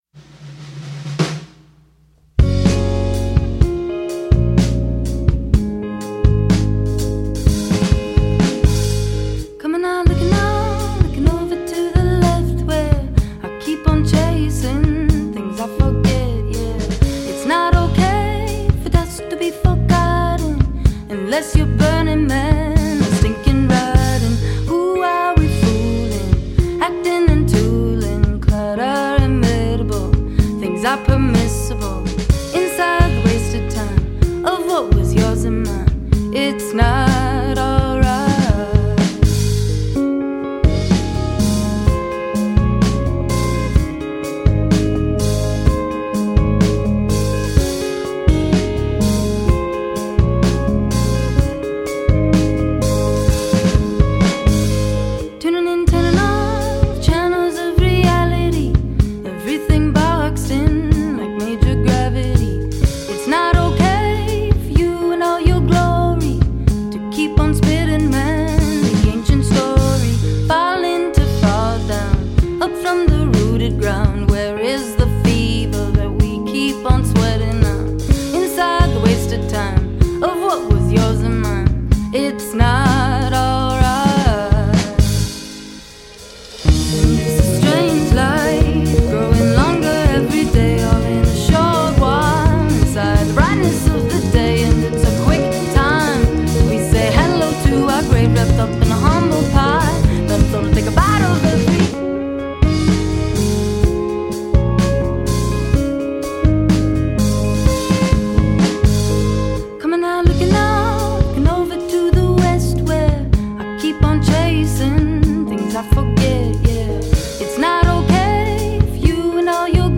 The five-piece Minneapolis-based band